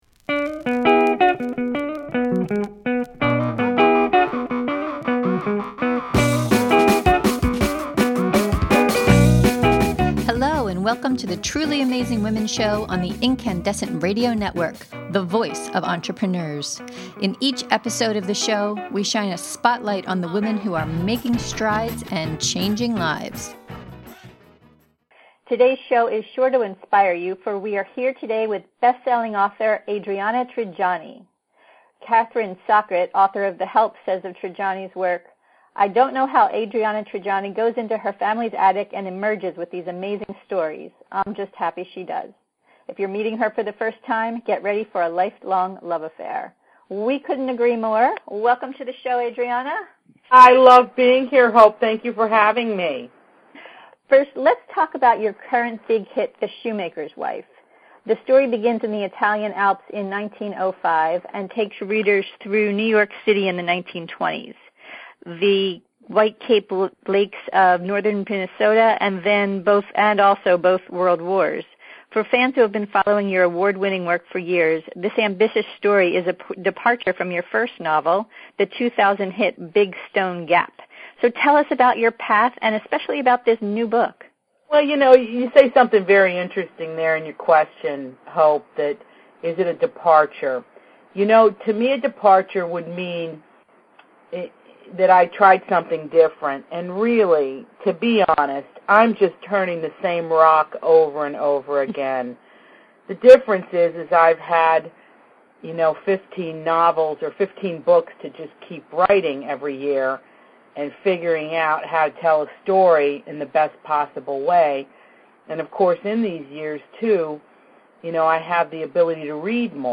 In this podcast interview we discuss: What life is like as a bestselling author? Where she gets her story ideas from? What it was like to be a writer for The Cosby Show?